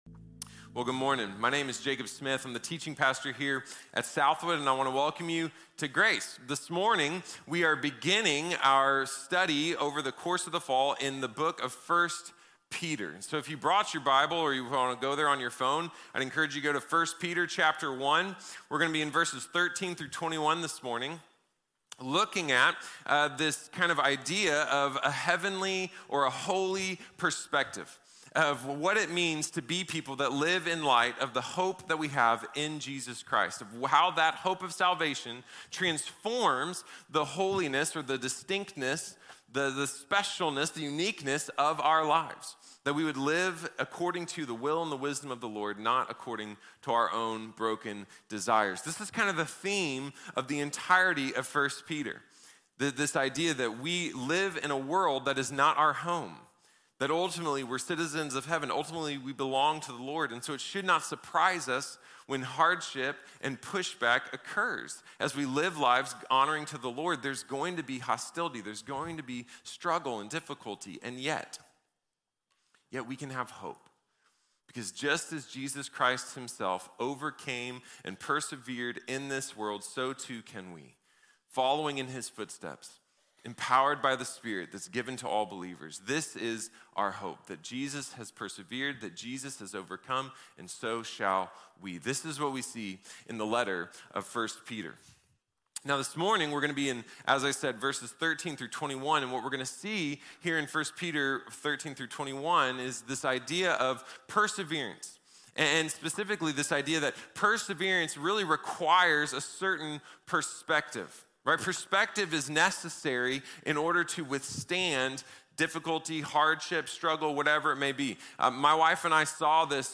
Hopeful Holiness | Sermon | Grace Bible Church